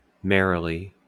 Ääntäminen
IPA : /ˈmɛɹɪli/